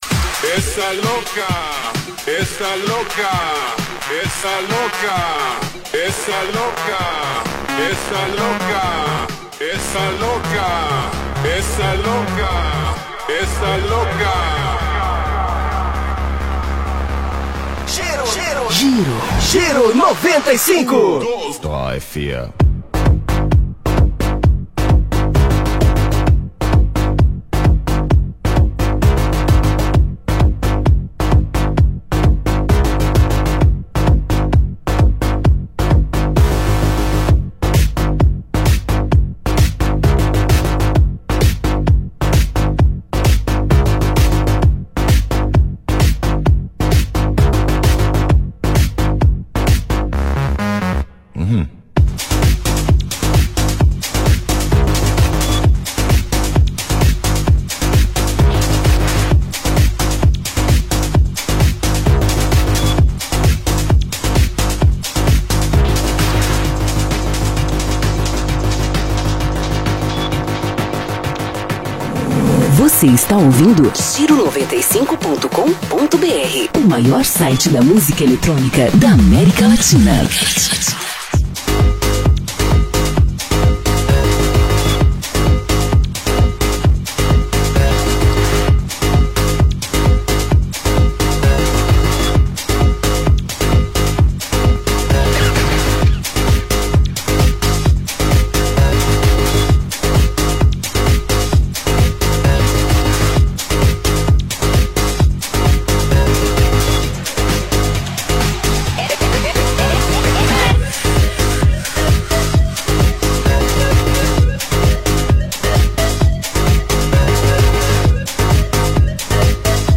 Recorde os anos de 2005, 2006 e 2007 com essa seleção mixada